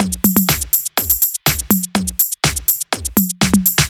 • drum machine roll and fill tech house.wav
drum_machine_roll_and_fill_tech_house_esz.wav